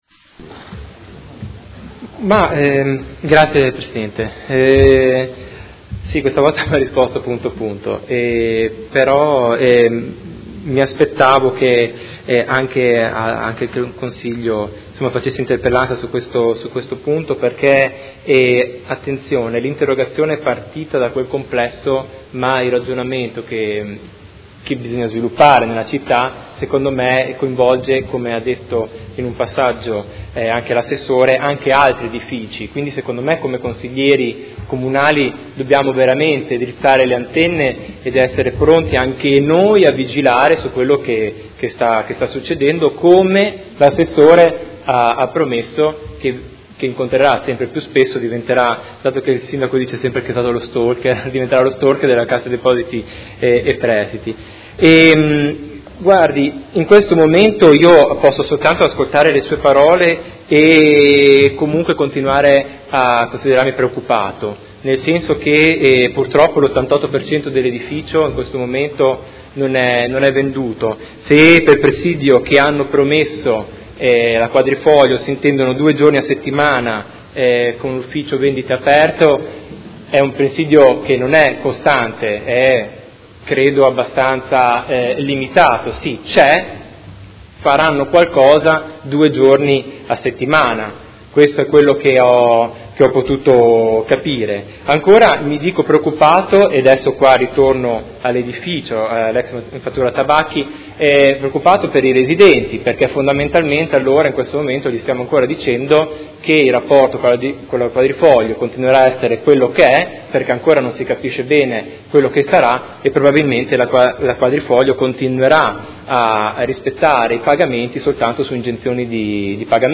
Seduta del 20/10/2016. Conclude interrogazione del Gruppo Per Me Modena avente per oggetto: Situazione del MATA, ex Manifattura Tabacchi